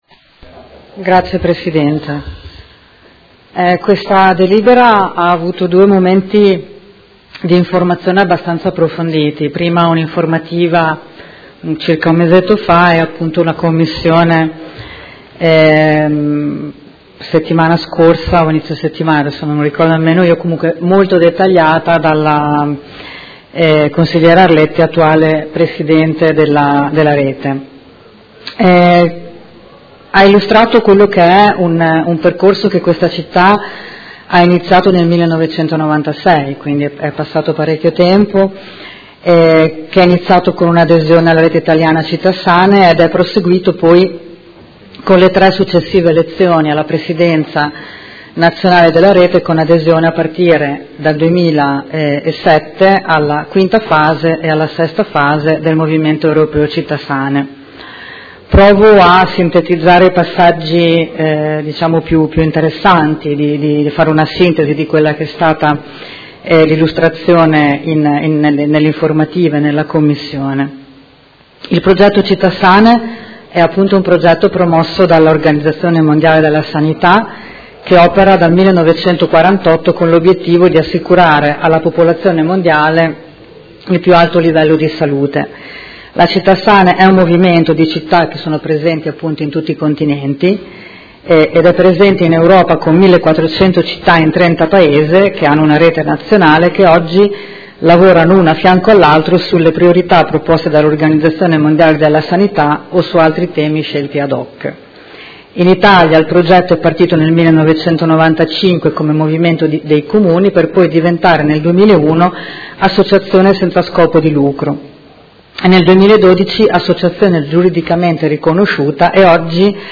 Seduta del 28/03/2019. Proposta di deliberazione: Candidatura della Città di Modena alla fase VII (2019-2024) del progetto “Città sane” proposto dall'O.M.S. - Organizzazione Mondiale della Sanità – Approvazione
Audio Consiglio Comunale